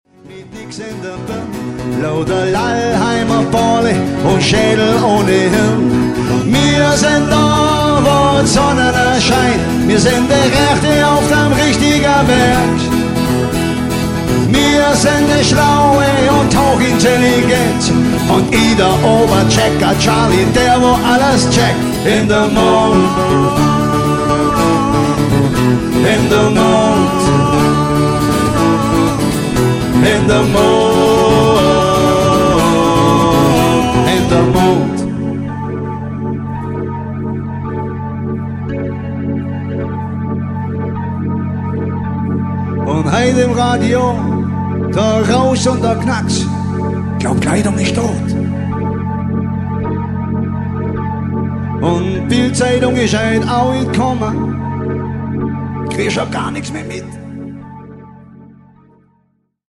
im Trio (live)